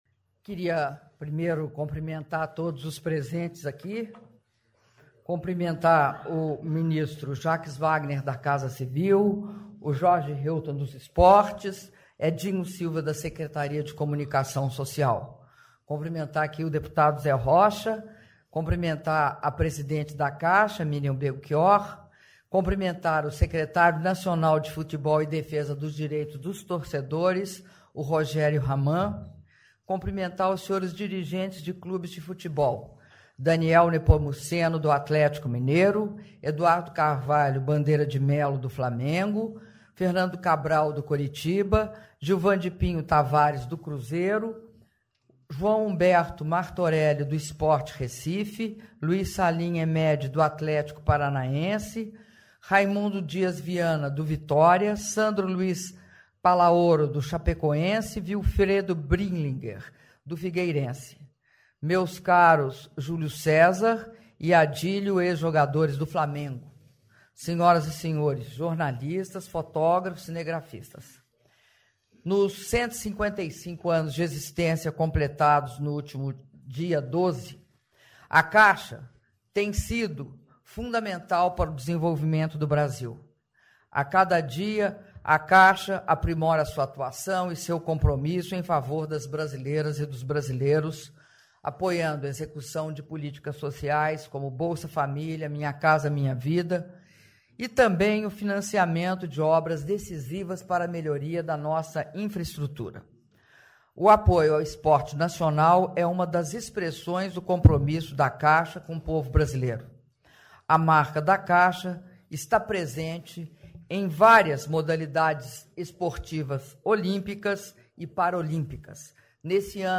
Áudio do discurso da Presidenta da República, Dilma Rousseff, durante cerimônia de assinatura de contratos de patrocínio de futebol- Brasília/DF (08min24s)